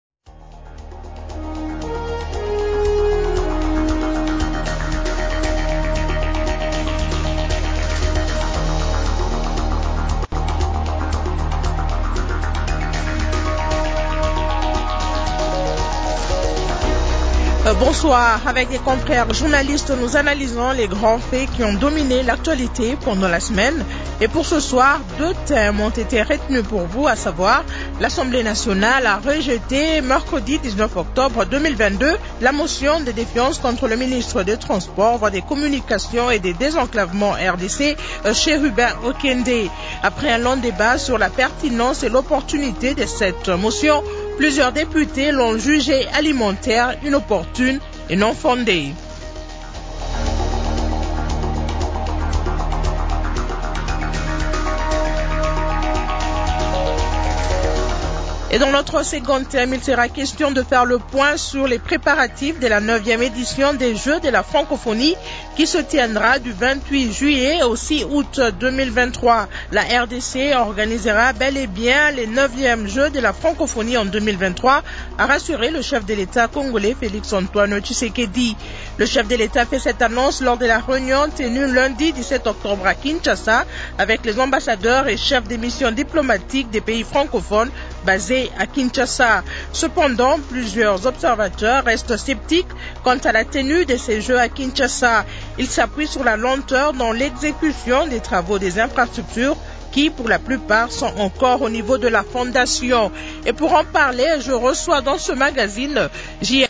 Invités